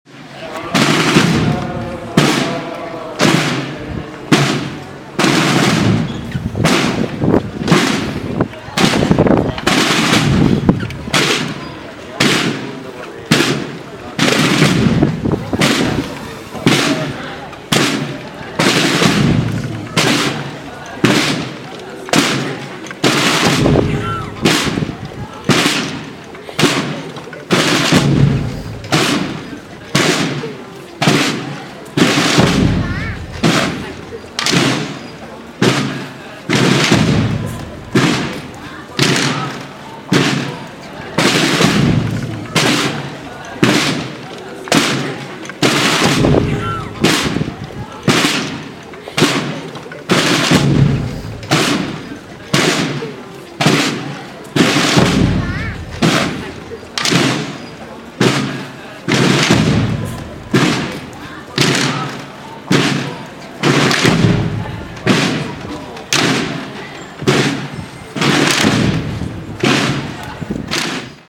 El pasado 28 de marzo tuvo lugar el Vía Crucis penitencial con la imagen del "Cristo del Despojo", organizado por la Hdad. de Jesús en el Calvario y Santa Cena.